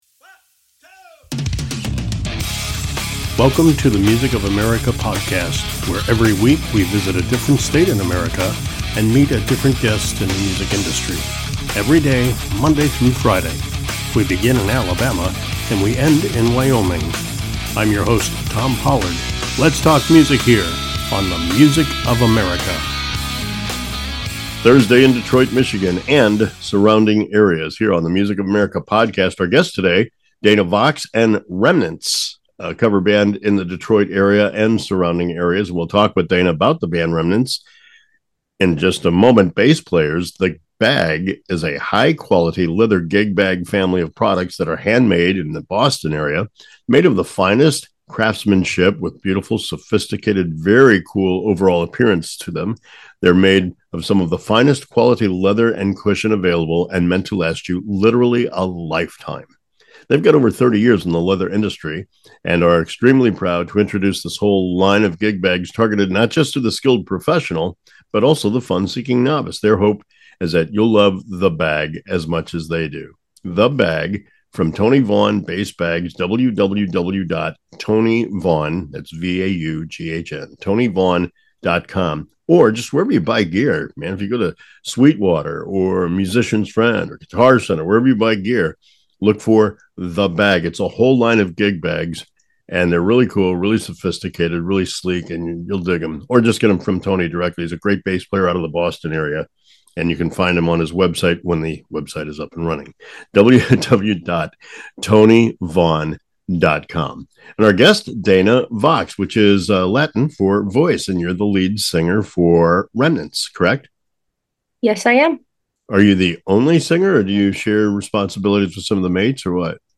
rockin' and rollin'